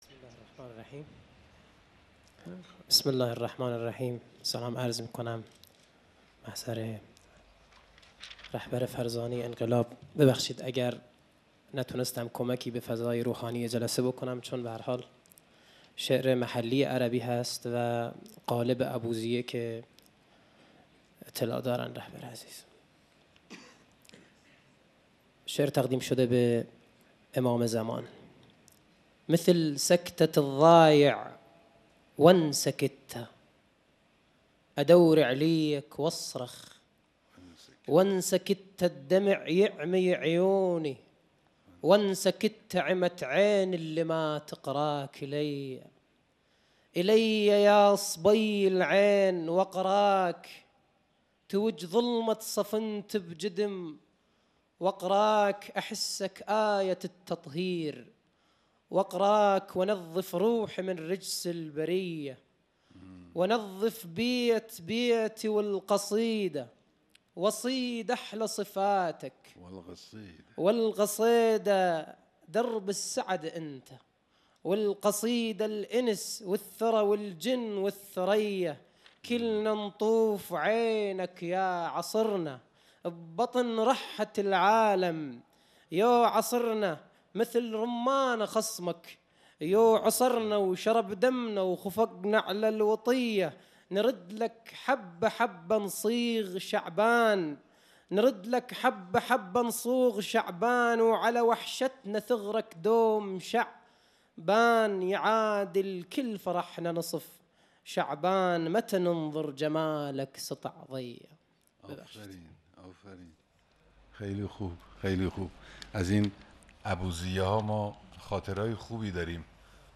شعر خوانی | مثل سکتت الضایع و نسکته
محفل شاعران آئینی | حسینیه امام خمینی(ره)